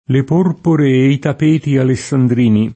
le p1rpore e i tap%ti aleSSandr&ni] (Monti) — solo tappeto nelle varie specificaz. e locuz. d’introduz. recente: tappeto mobile, tappeto volante; tappeto da preghiera, tappetino da bagno; bombardamento a tappeto, indagini a tappeto